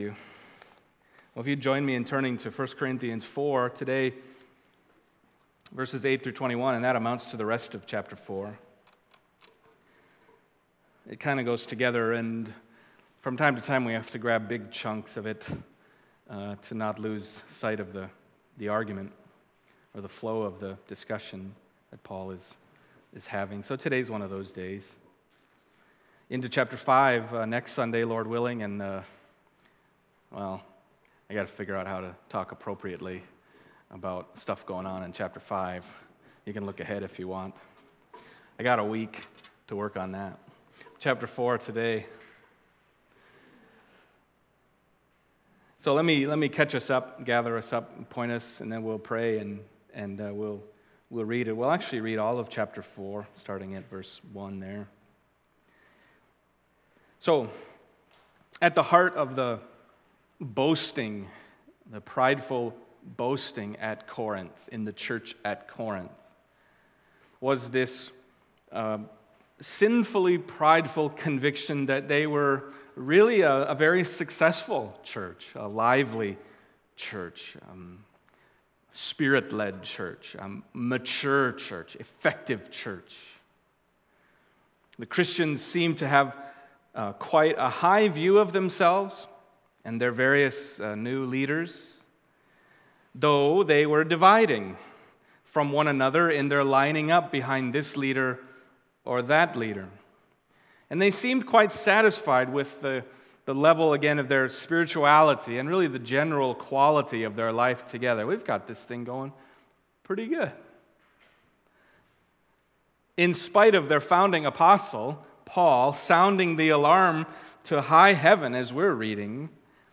Passage: 1 Corinthians 4:8-21 Service Type: Sunday Morning